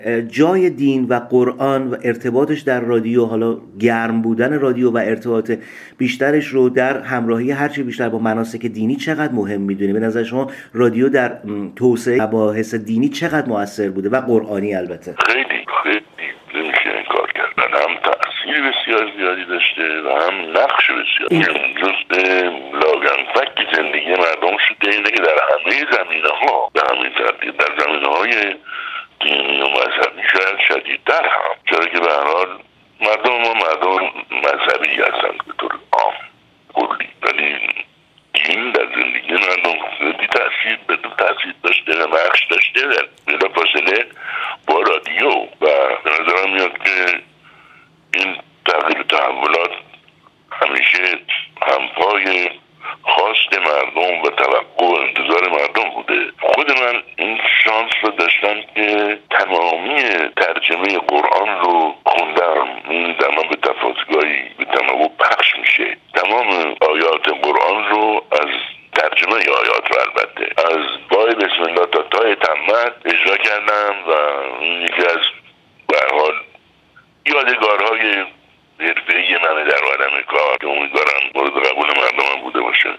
بهروز رضوی در گفت‌وگو با ایکنا: